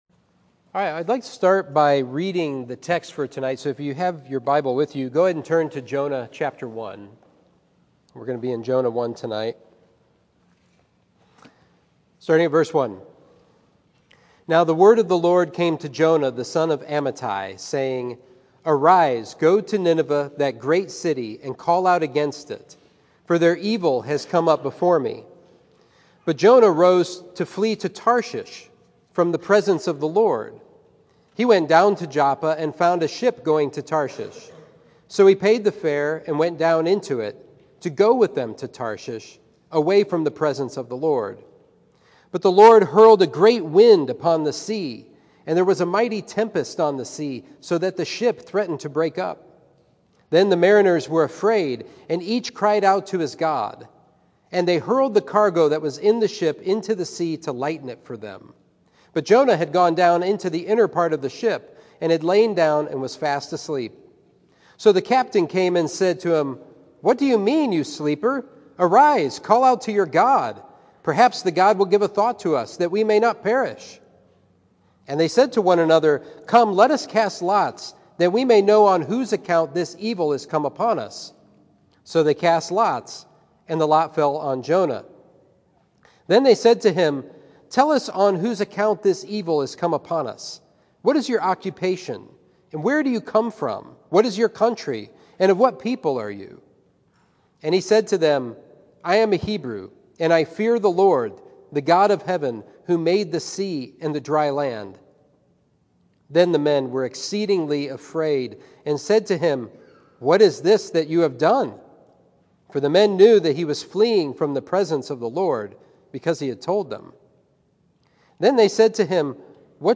Sermon 5/9: Jonah: In the Boat of Life